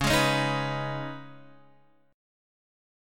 C#7sus2 chord